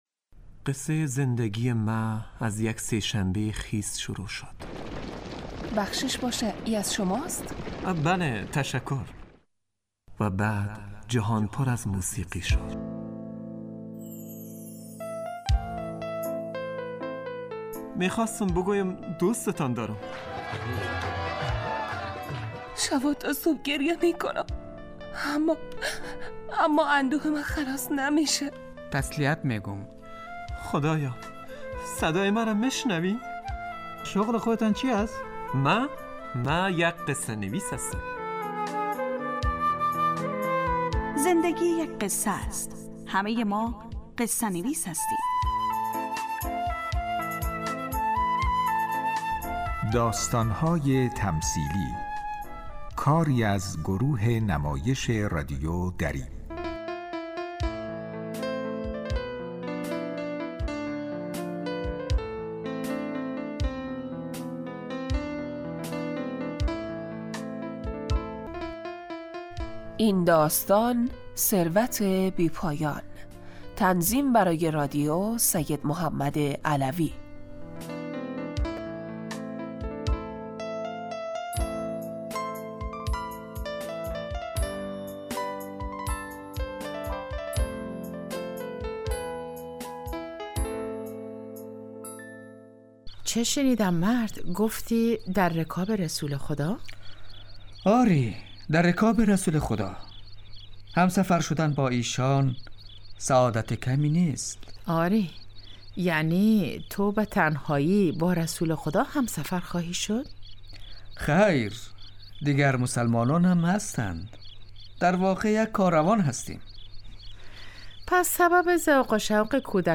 داستان های تمثیلی یک برنامه 15 دقیقه ای در قالب نمایش رادیویی می باشد که همه روزه به جز جمعه ها از رادیو دری پخش می شود موضوع اکثر این نمایش ها پرداختن به مسائل و مشکلات روز افغانستان از جمله زنان می باشد .. داستان های تمثیلی سعی می کند قصه هایش به زندگی مردم نزدیک باشد و علاوه بر اینکه سرگرم کننده باشد تلنگری باشد برای مخاطبین و شنونده ها برای تغییر به سمت بهتر شدن و تصمیمات بهتر گرفتن